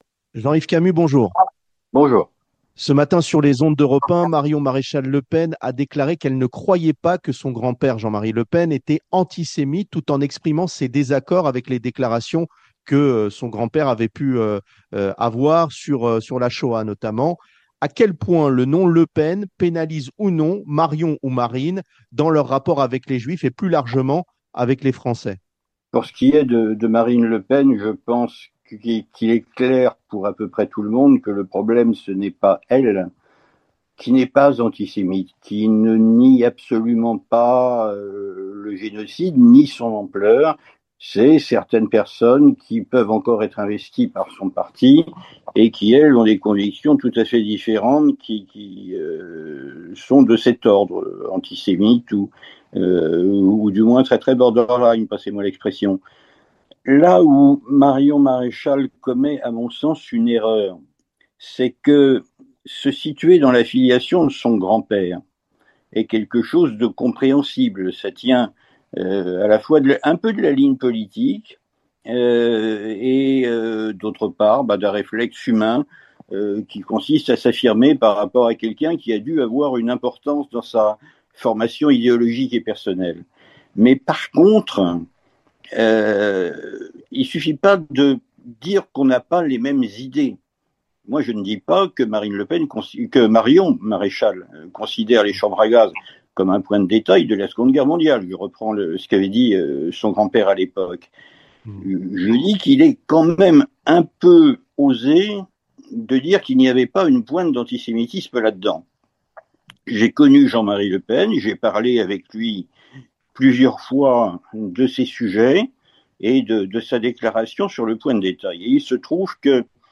Pour en parler on reçoit Jean-Yves Camus, chercheur associé à l'IRIS et spécialiste des nationalismes et extrémismes en Europe.